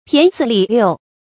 骈四俪六 pián sì lì liù
骈四俪六发音
成语注音 ㄆㄧㄢˊ ㄙㄧˋ ㄌㄧˋ ㄌㄧㄨˋ
成语正音 骈，不能读作“bìnɡ”。